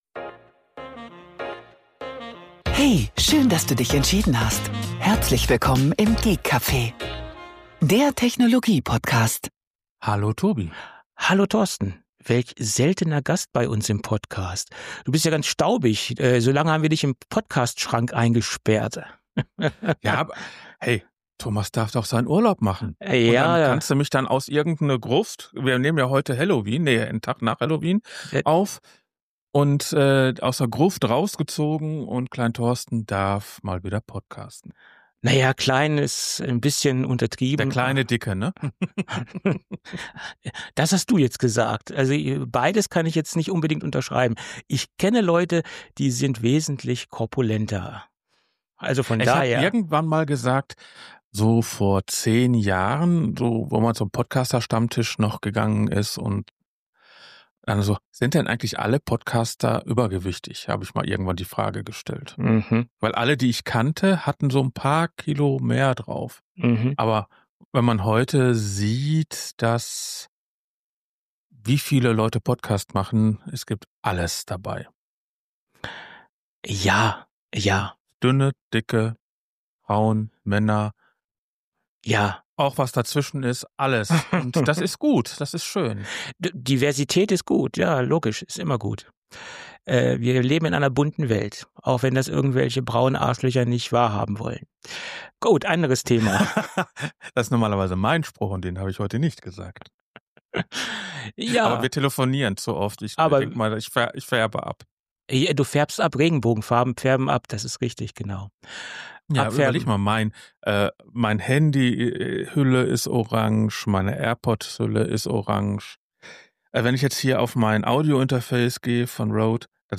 Die lockere Atmosphäre während der Aufnahme ist dem Konzept – oder eben dem Fehlen desselbigen zu verdanken.